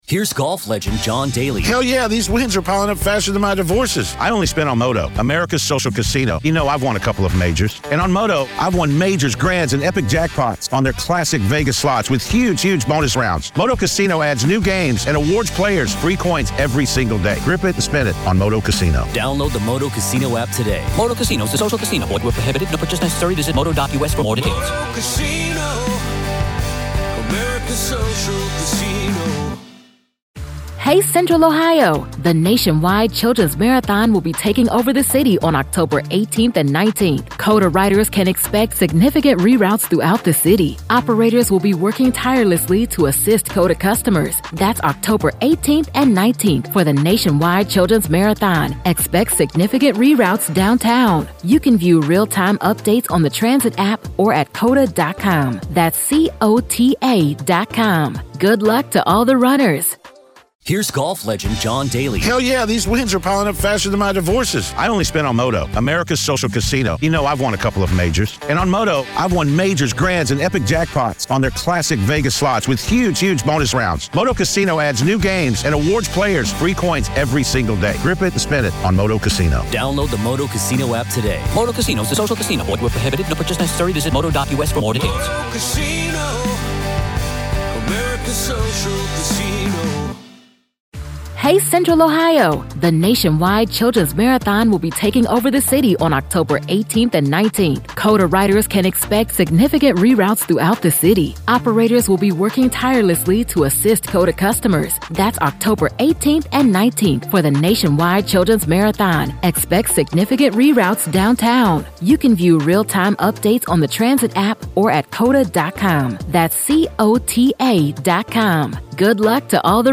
criminal defense attorney and former prosecutor